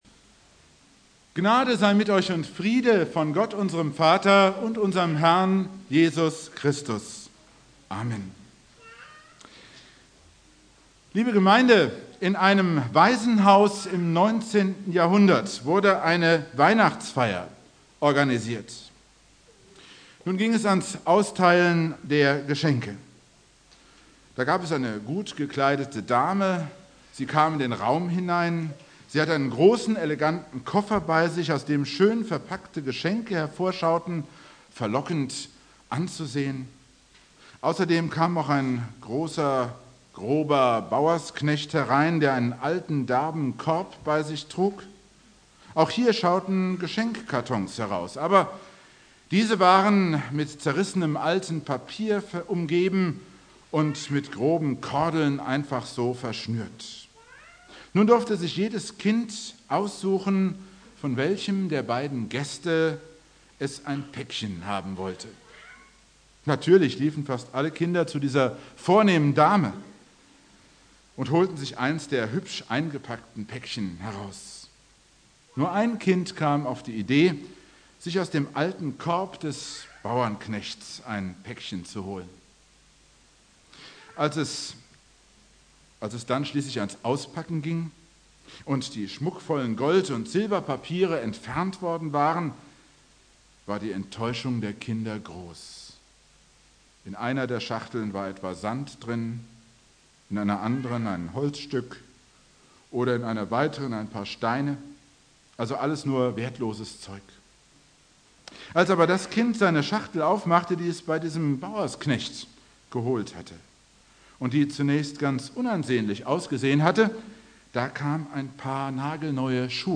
Predigt
- Heiligabend 16:30 Uhr Dauer: 17:28 Abspielen: Ihr Browser unterstützt das Audio-Element nicht.